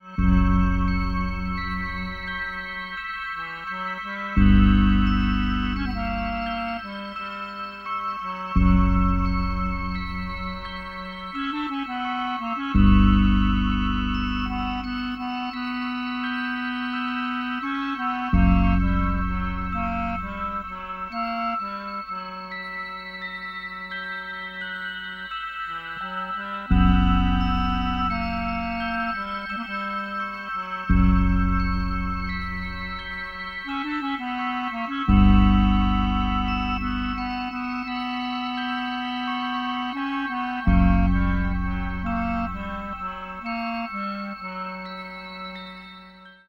F# major